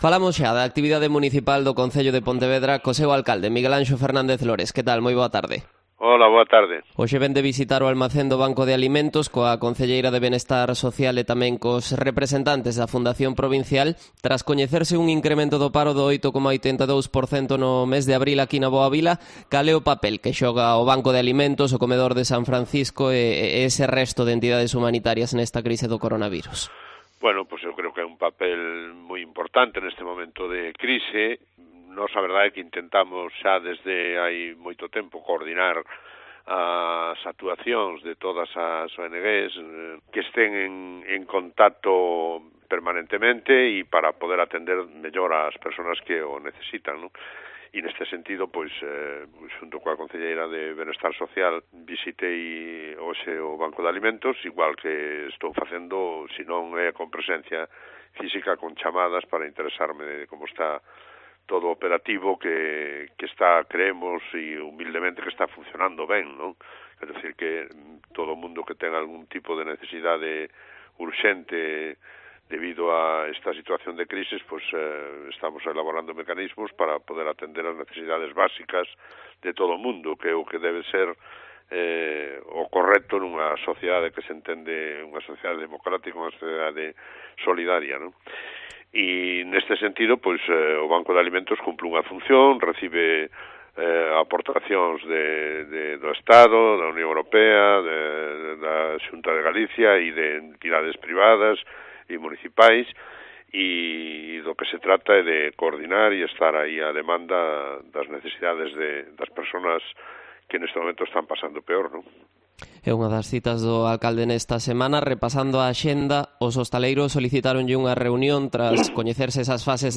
Entrevista a Miguelanxo Fernández Lores, alcalde de Pontevedra